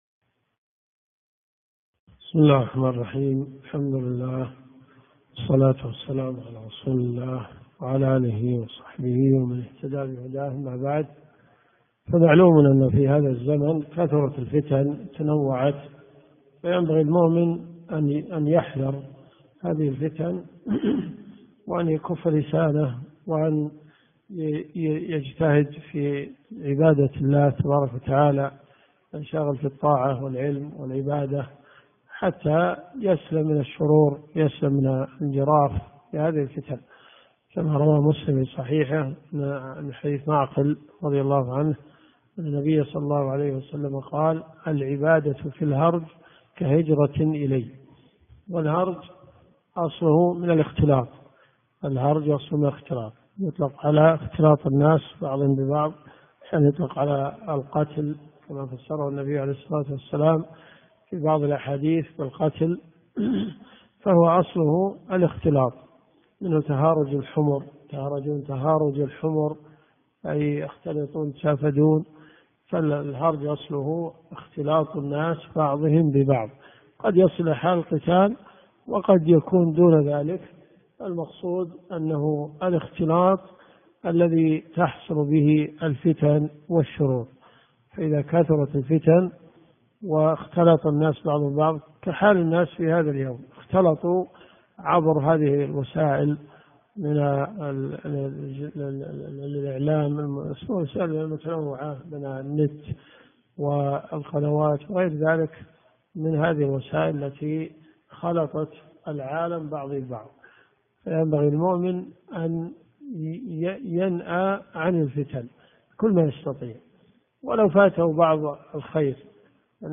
الدرس في الدقيقة 3.50 .